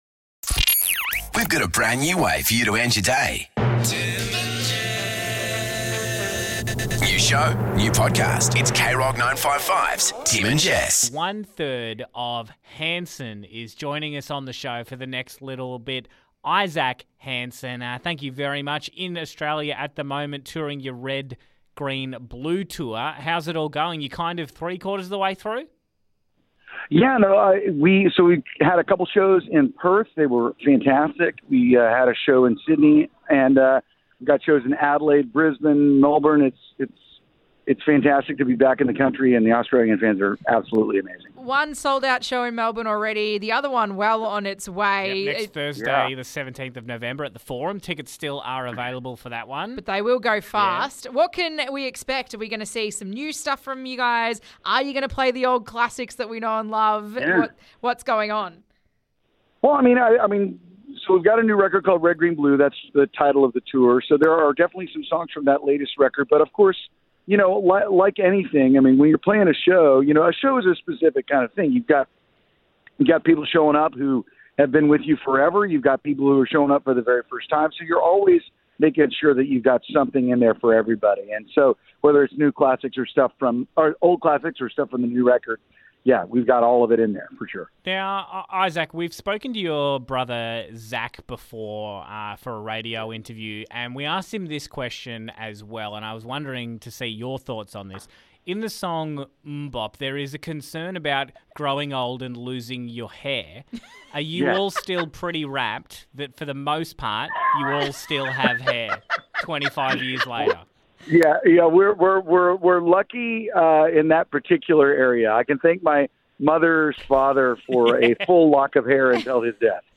GUEST: Isaac Hanson from HANSON wants to be a TOOLIE?!